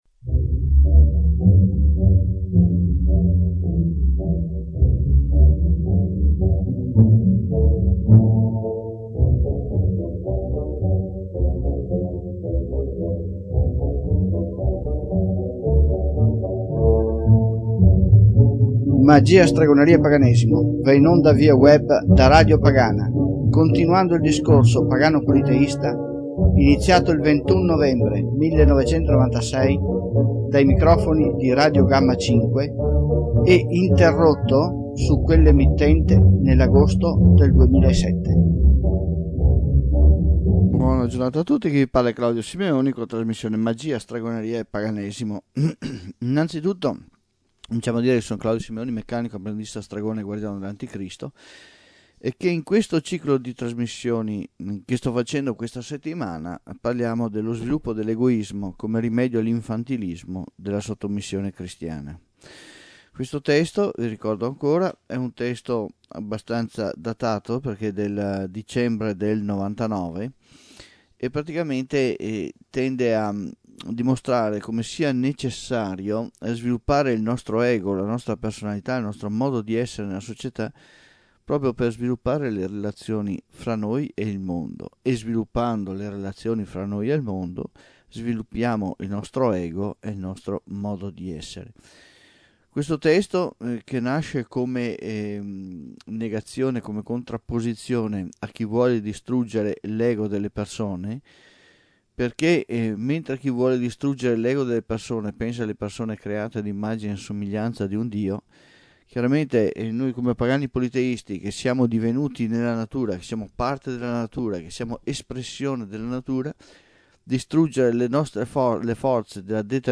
C'era un grande disordine mentre trasmettevo